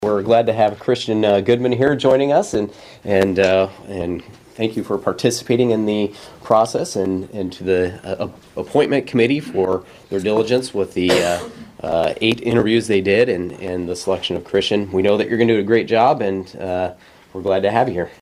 Yesterday before the Marshall County Board of Supervisors meeting, Christian Goodman was officially sworn in to fill his father’s, Kevin Goodman’s seat.  Chairman, Jaret Heil kicked off the meeting thrilled that the seat is now filled.